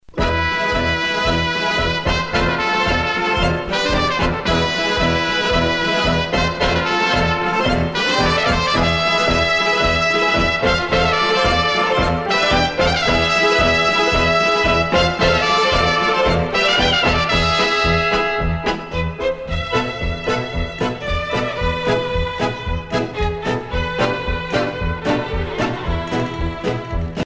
paso-doble
Pièce musicale éditée